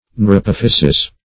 neurapophyses.mp3